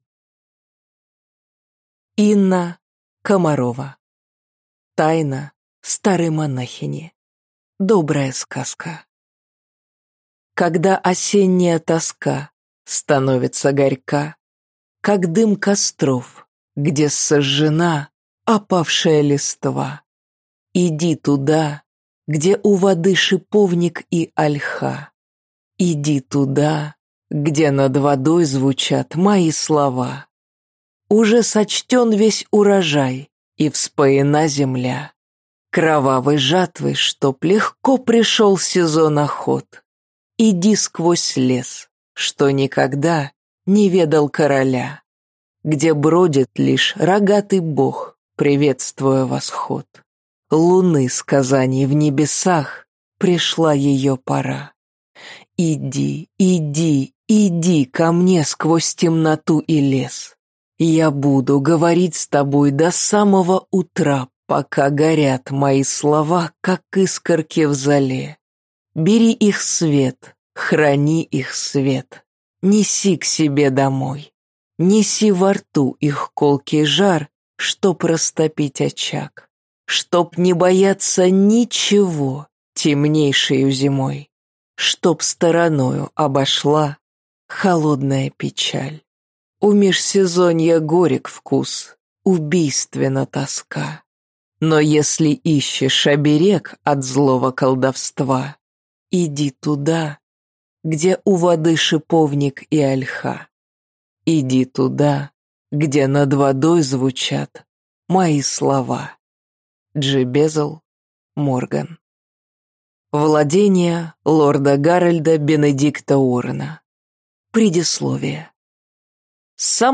Аудиокнига Тайна старой монахини. Добрая сказка | Библиотека аудиокниг